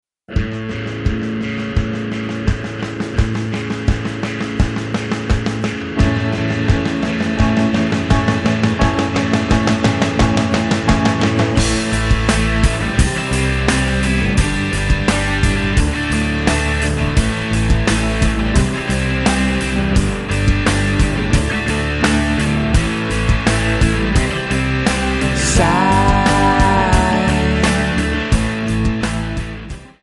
Backing track files: Rock (2136)